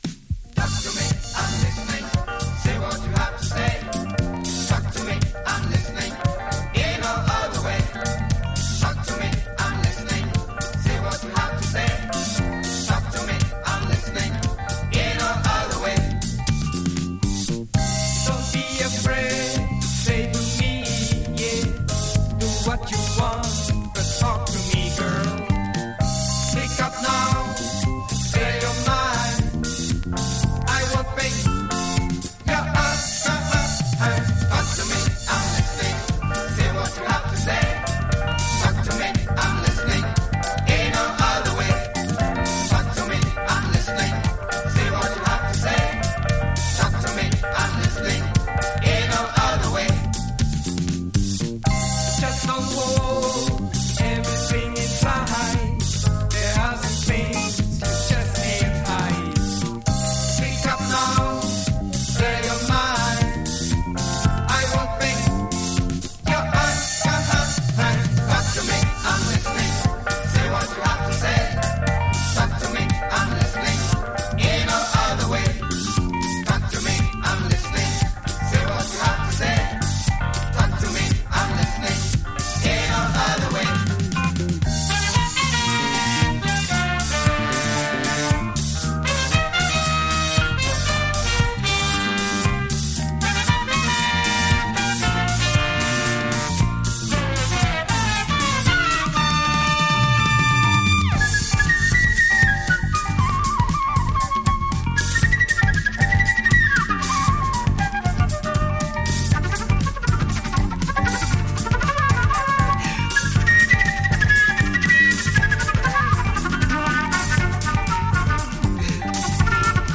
Flute, Lead Vocals, Tenor Saxophone
Vocals, Electric Piano, Synthesizer
Bass Guitar
Rhythm Guitar
Congas
Drums, Percussion
Lead Guitar